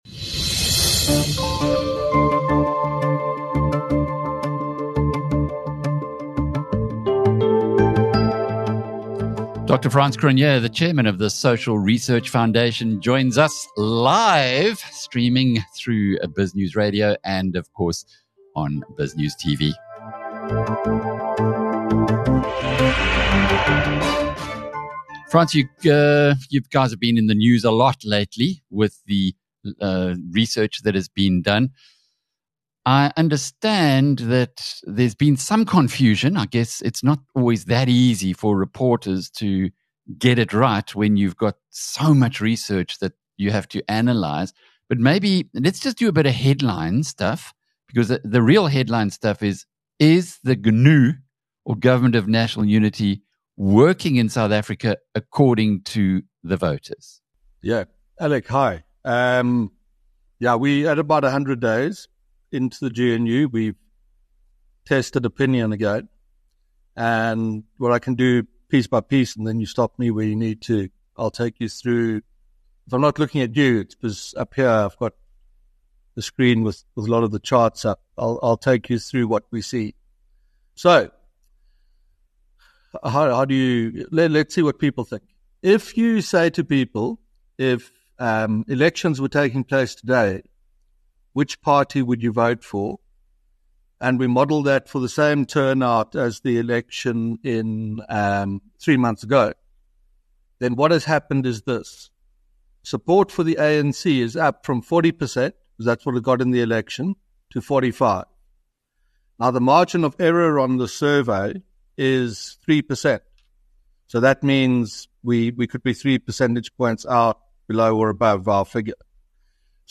In a recent interview on BizNews Radio